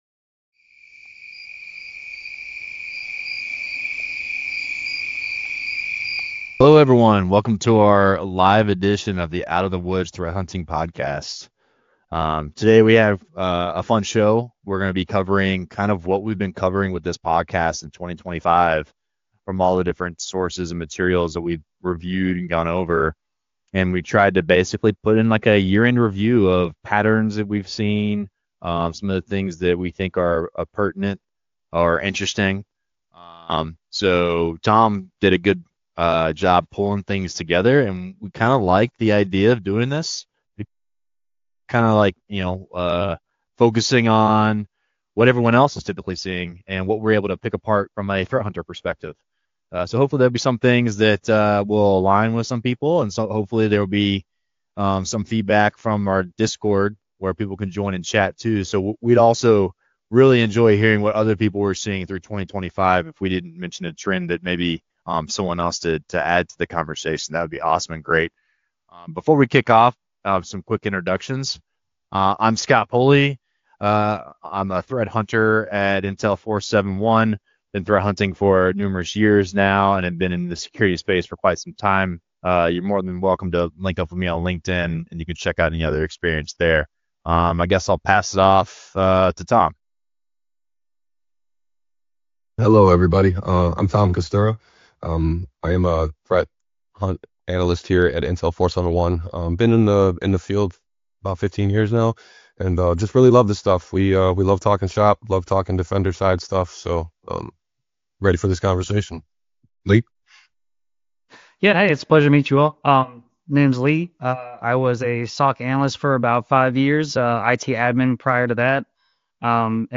Out of the Woods: The Threat Hunting Podcast returned with a live episode focused on the trends threat hunters saw repeatedly throughout 2025 and what those patterns point to next.
Based on what emerged in 2025 and how hunts played out across environments, the panel shares perspectives on what is likely to continue, where focus is expected to remain in 2026, and what threat hunters should keep in mind going forward.